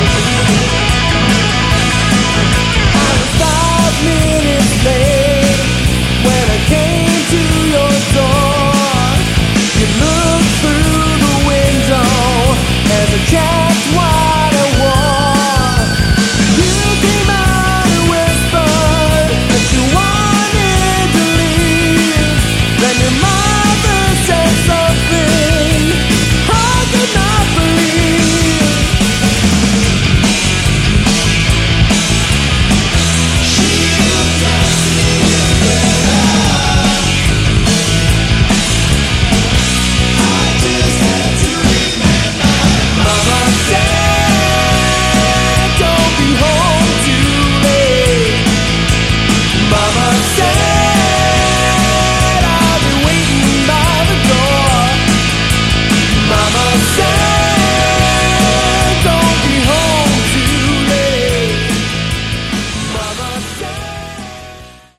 Category: Hard Rock
bass
guitar
vocals
drums